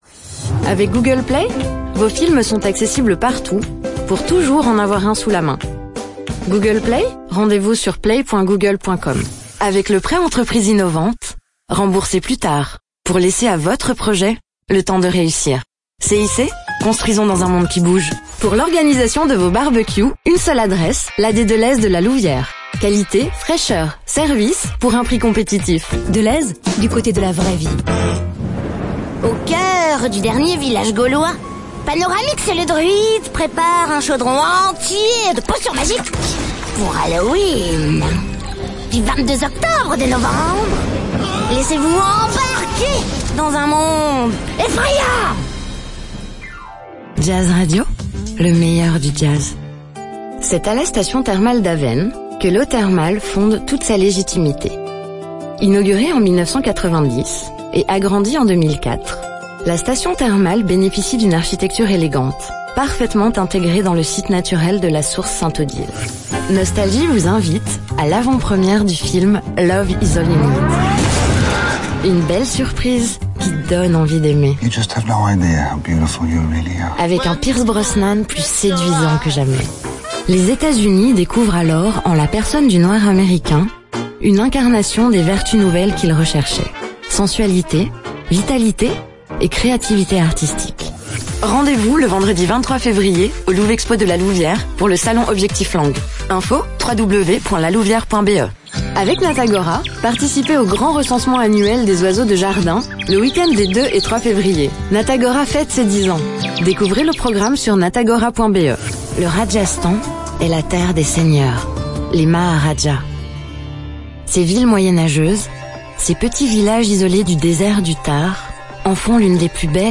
Fiable
Chaleureux
Amical